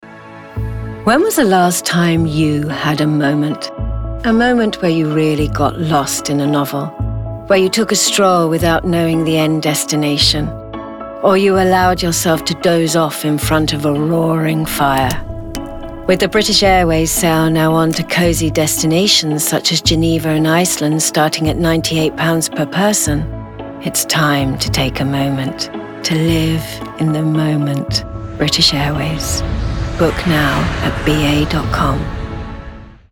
Voice Reel
Calming, Inviting, Engaging